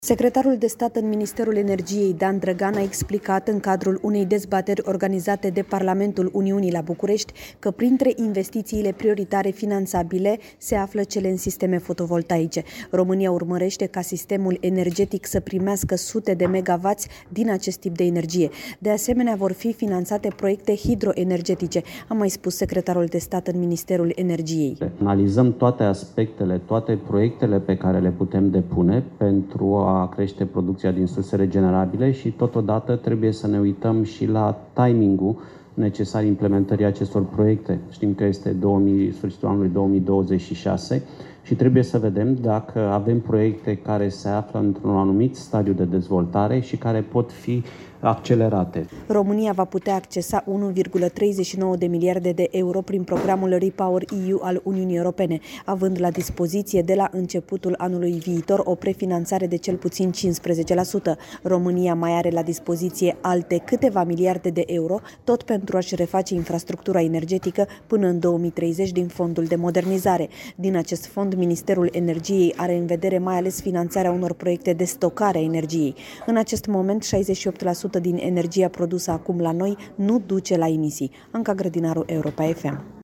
De asemenea, vor fi finanțate proiecte hidroenergetice, a mai spus secretarul de stat în Ministerul Energiei, Dan Drăgan: